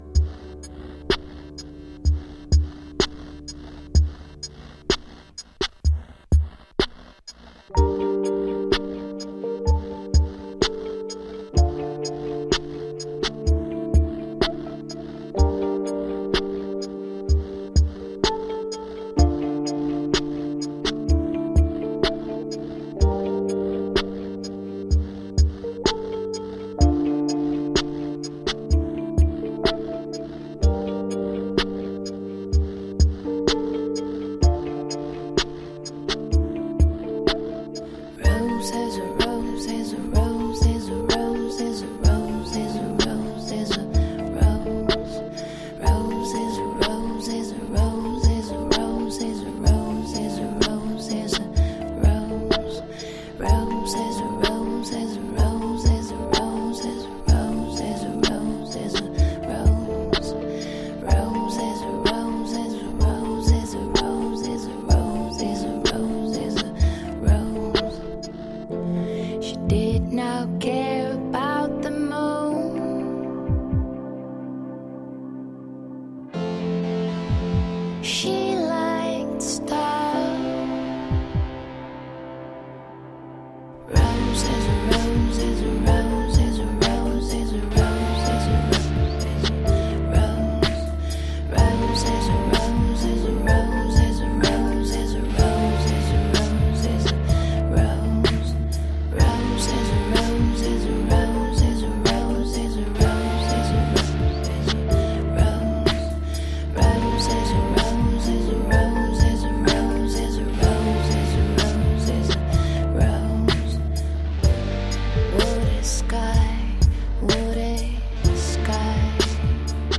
Alternative, pop, electronic, rock, trip-hop from italy.
Tagged as: Alt Rock, Folk-Rock, Pop